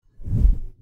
Звуки исчезновения
От таинственных магических эффектов до забавных фантастических переходов — здесь есть всё для создания атмосферы.
Звук резкого исчезновения для монтажа